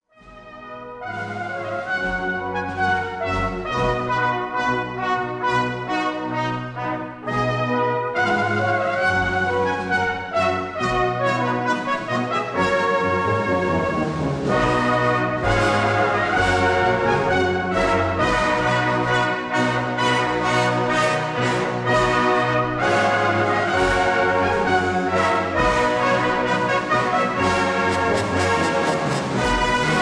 brass bands
1960 stereo recording